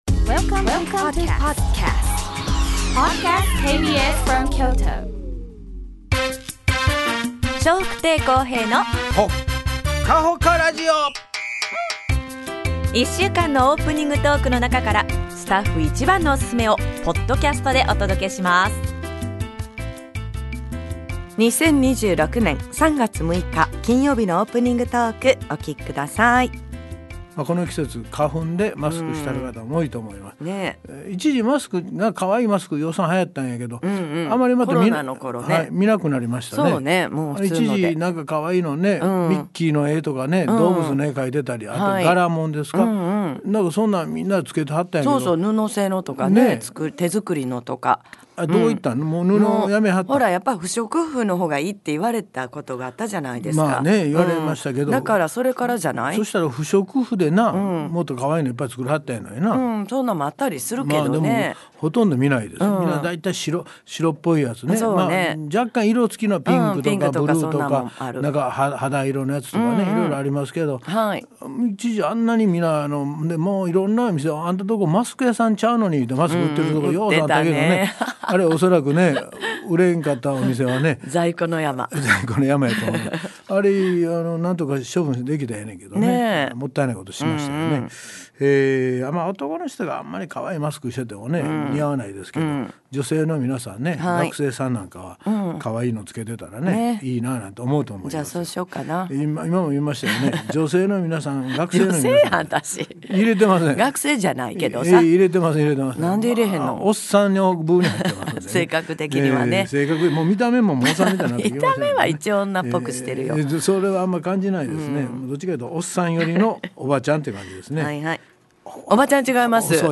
2026年3月24日のオープニングトーク